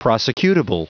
Prononciation du mot prosecutable en anglais (fichier audio)
prosecutable.wav